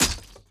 Sword Impact Hit 2.ogg